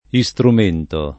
iStrum%nto] s. m. — variante, in genere, lett. di strumento; quasi più com. però nel sign. di «documento notarile» — antiq. (anche in quest’ultimo sign.) instrumento [inStrum%nto]: notaio che legga in volgare lo instrumento che egli dettò latino [not#Lo ke ll$gga in volg#re lo inStrum%nto ke el’l’i dett0 llat&no] (Della Casa)